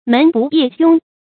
门不夜扃 mén bù yè jiōng
门不夜扃发音
成语注音 ㄇㄣˊ ㄅㄨˋ ㄧㄜˋ ㄐㄩㄥ